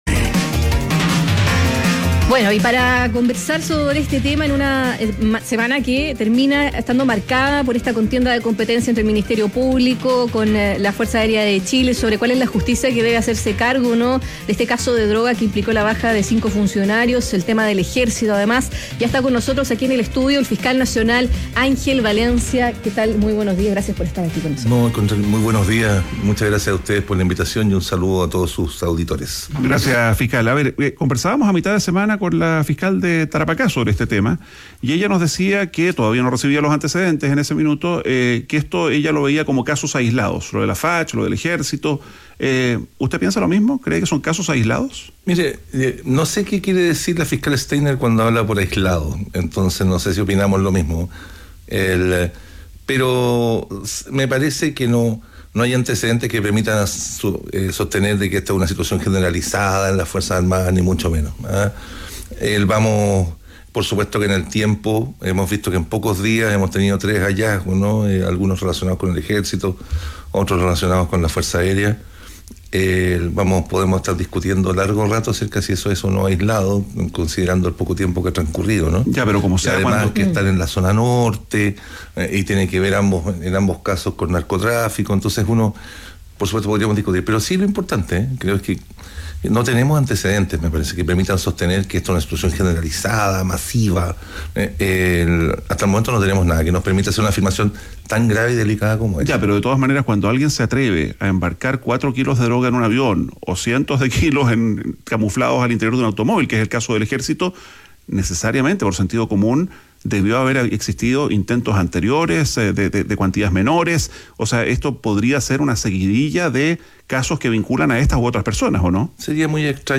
Entrevista a fiscal nacional, Ángel Valencia - ADN Hoy